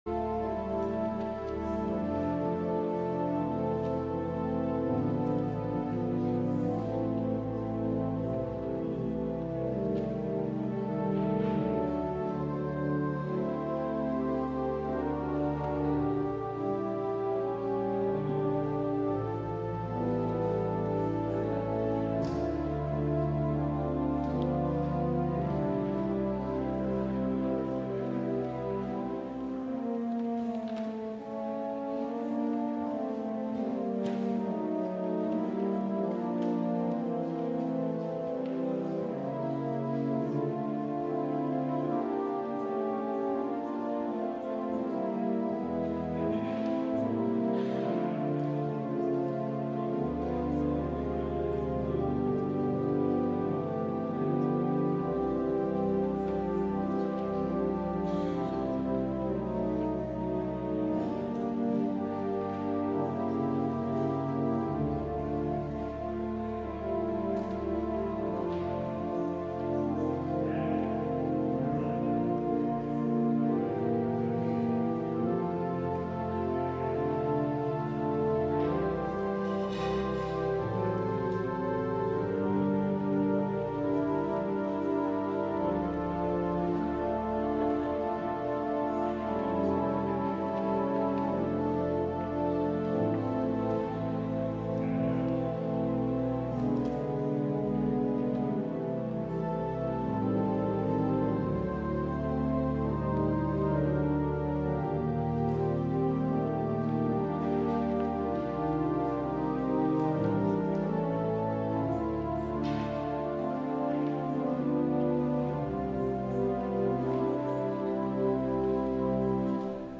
LIVE Morning Worship Service - The Prophets and the Kings: The Call of Elijah